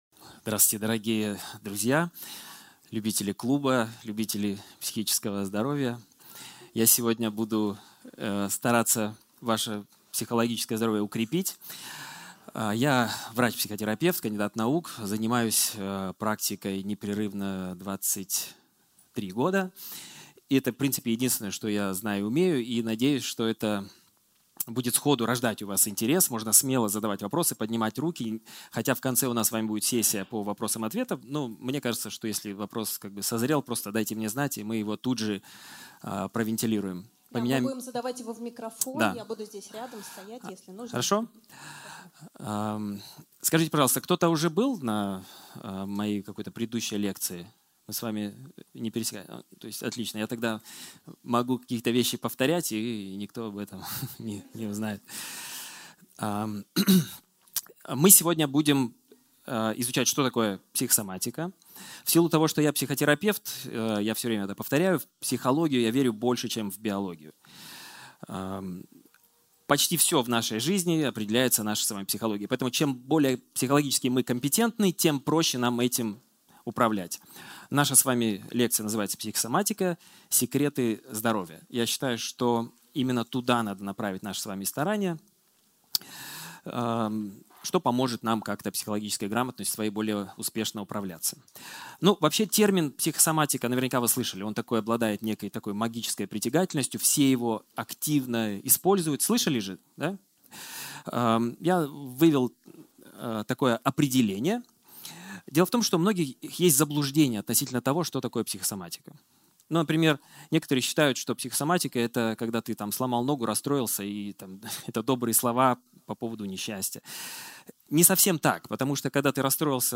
Аудиокнига Психосоматика, секреты здоровья | Библиотека аудиокниг